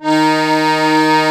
D#3 ACCORD-L.wav